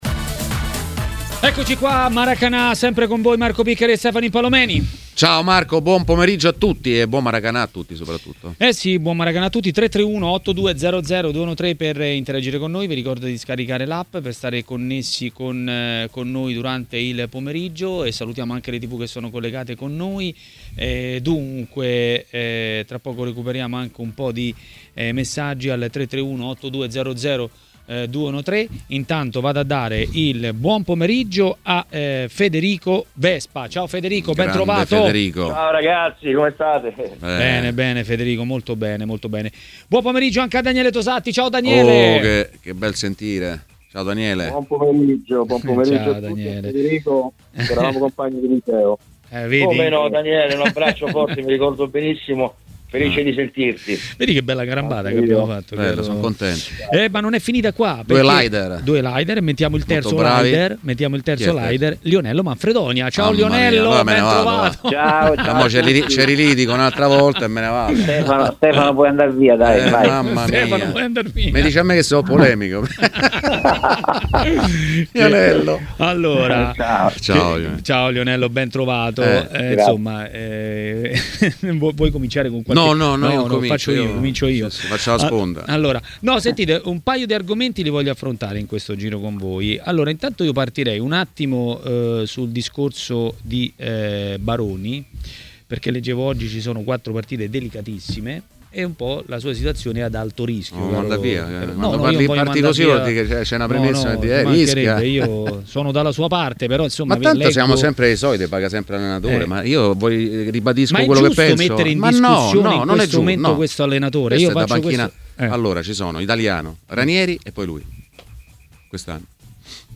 A parlare dei temi del giorno a TMW Radio, durante Maracanà, è stato l'ex calciatore Lionello Manfredonia.